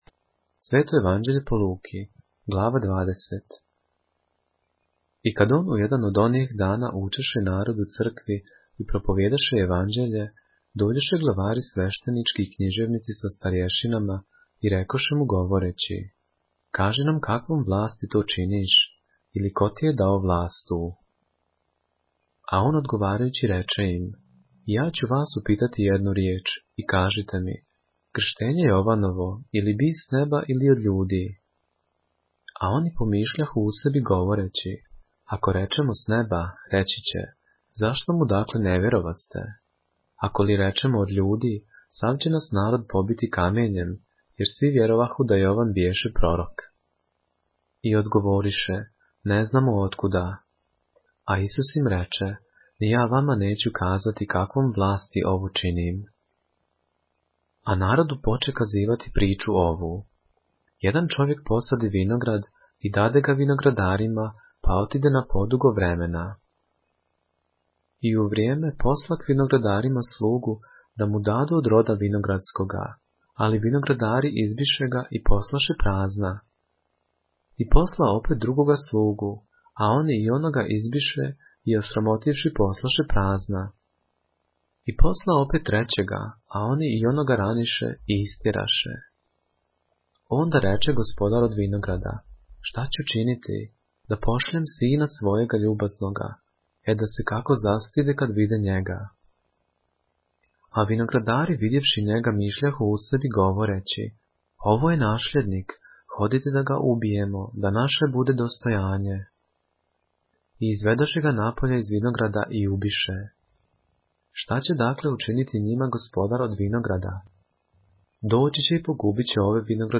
поглавље српске Библије - са аудио нарације - Luke, chapter 20 of the Holy Bible in the Serbian language